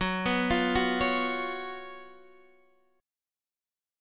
F#M7sus4 chord